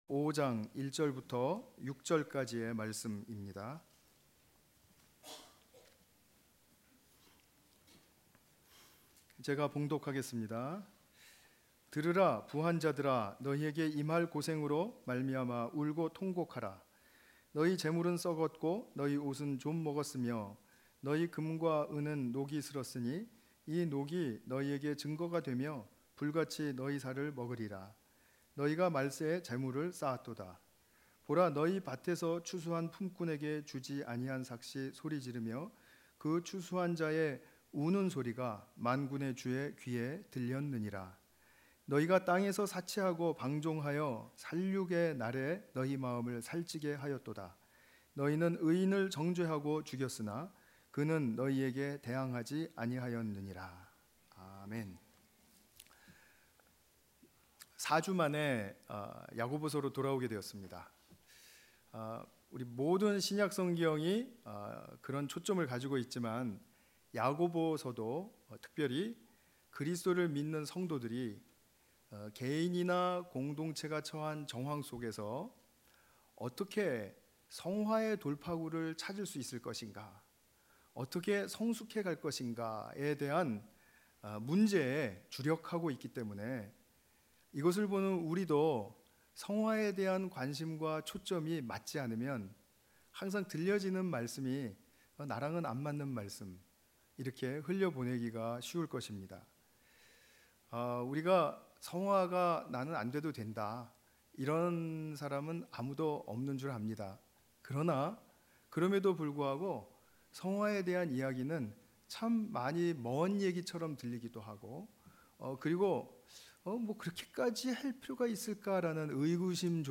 관련 Tagged with 주일예배 Audio (MP3) 58 MB PDF 361 KB 이전 네가 나를 사랑하느냐?